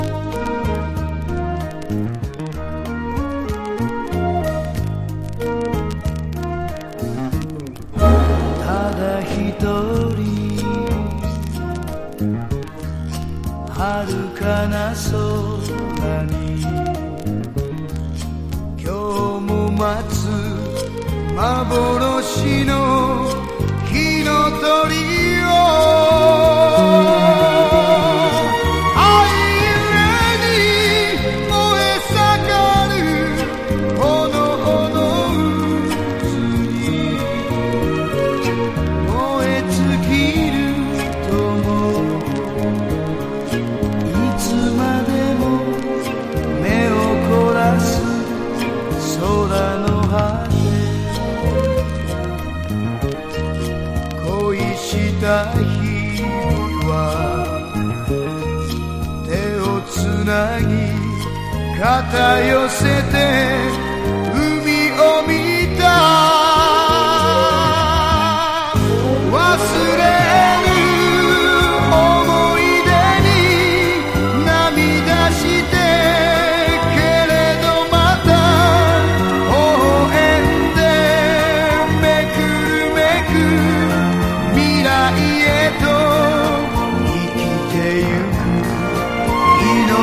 和モノ / ポピュラー